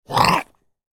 Zombie Bite Sound Effect
Experience the chilling realism of this Zombie Bite Sound Effect, featuring a short bite of a cartoon monster or zombie with creepy chewing and growling. Perfect for horror scenes, games, or Halloween projects that need a scary yet fun undead atmosphere.
Zombie-bite-sound-effect.mp3